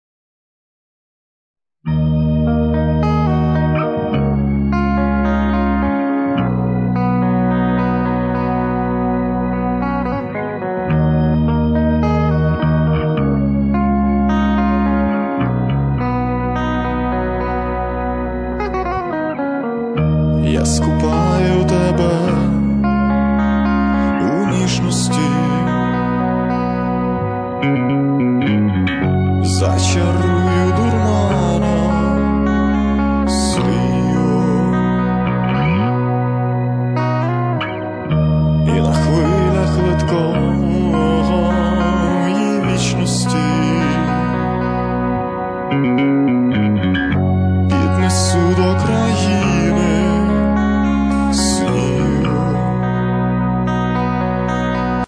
Rock (320)